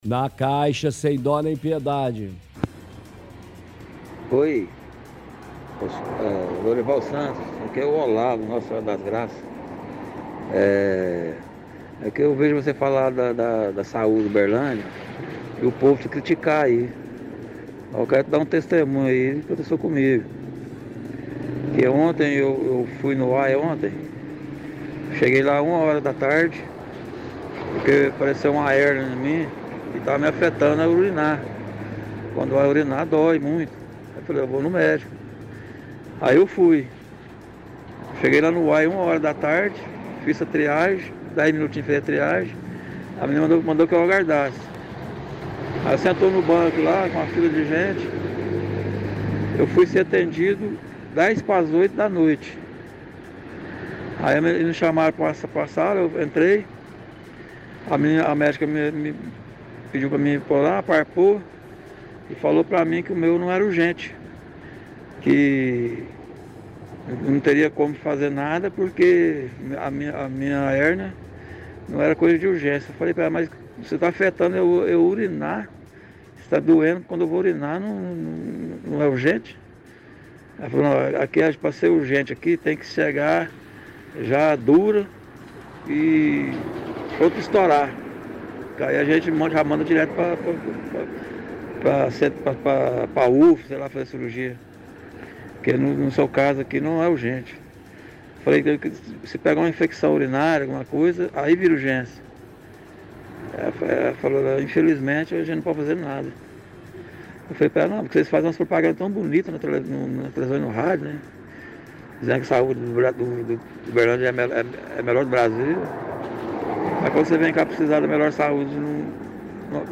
– Ouvinte reclama que compareceu a UAI as 13:00 da tarde e só foi atendido às 19:50 em função de hérnia, mas a médica somente falou que o caso não era urgente e não tinha o que ser feito.